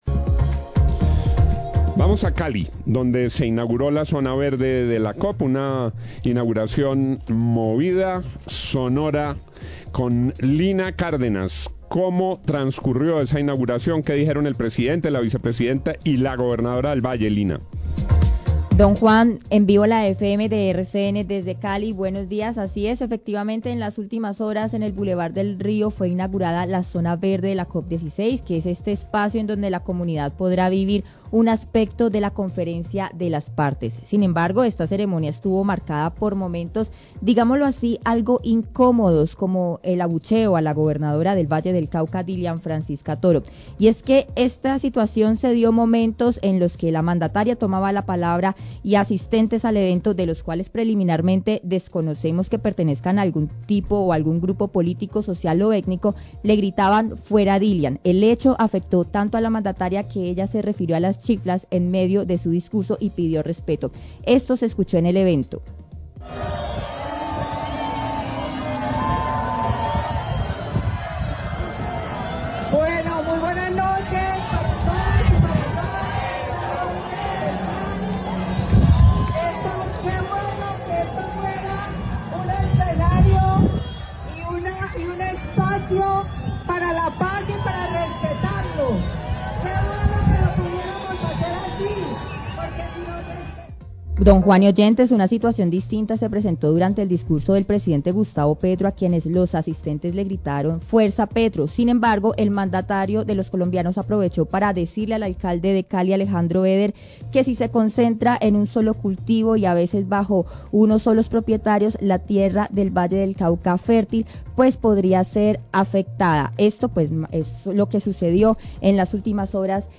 Gobernadora del Valle fue abucheada durante intervención en Zona verde, La FM, 605am
Durante la inauguración de la Zona Verde de la COP16 en el bulevar del río, la Gobernadora del Valle pidió respeto a los presentes luego de ser abucheada durante su intervención. Durante su intervención el presidente Gustavo Petro aprovechó para decirle al alcalde Eder que el monocultivo de la caña podría afectar la fertilidad de las tierras del Valle del Cauca.